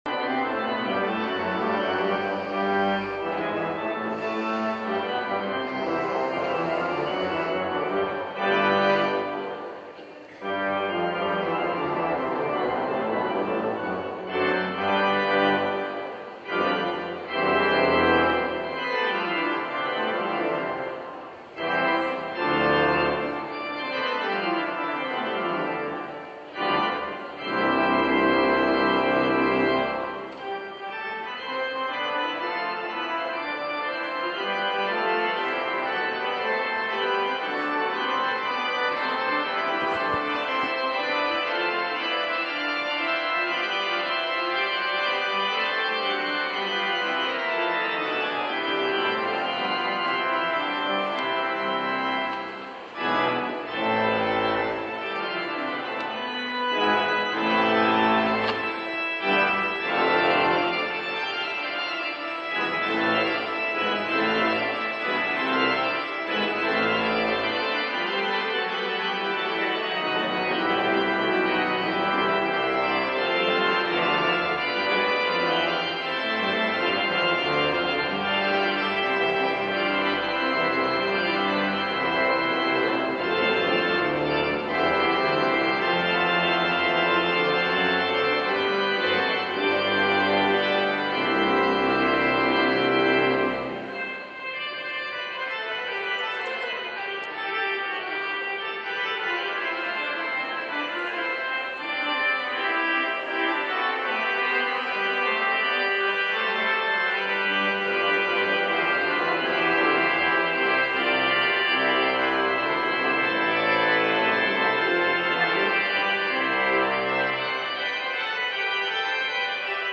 Some Bloody Amazing Organ Music, for Those of You Who Enjoy Such Things
It's the Prelude, Fugue and Ciacona by Dietrich Buxtehude. My church's organist/music director is incredibly talented, so I had to share.